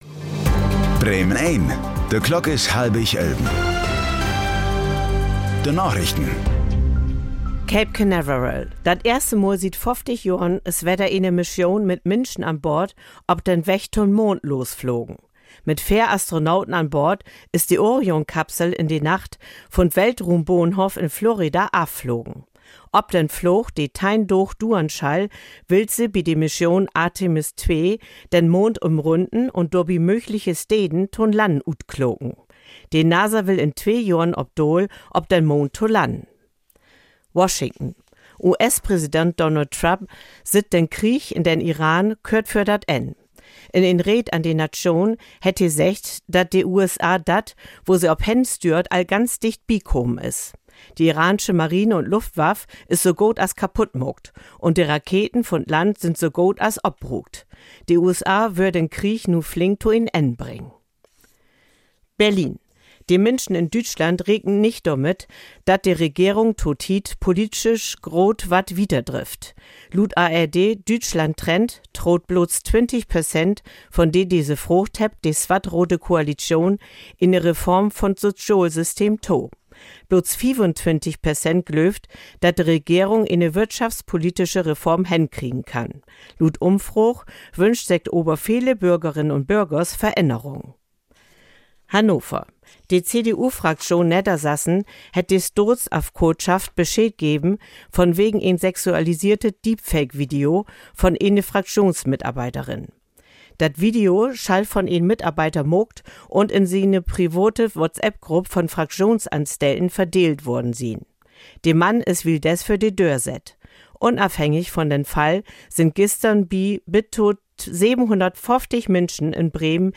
Plattdeutsche Nachrichten